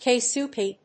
/ˌkeˈsuki(米国英語), ˌkeɪˈsu:ki:(英国英語)/